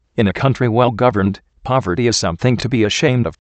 Location: USA
How do you pronounce this word?